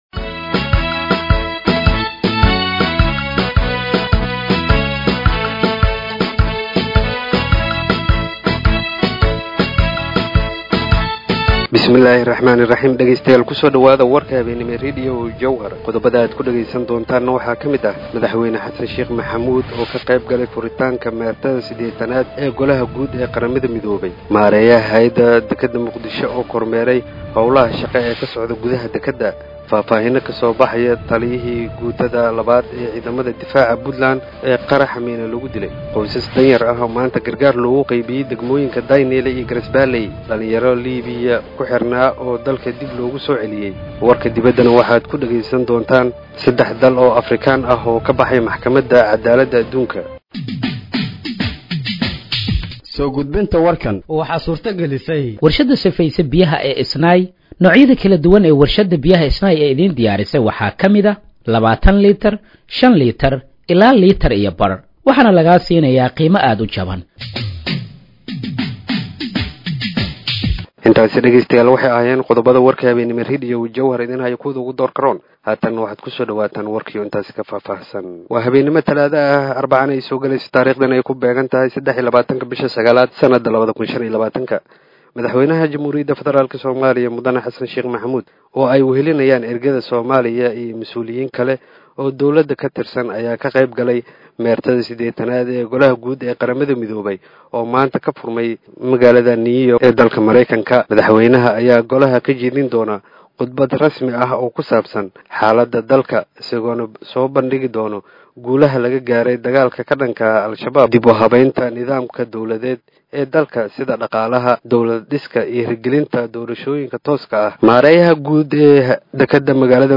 Dhageeyso Warka Habeenimo ee Radiojowhar 23/09/2025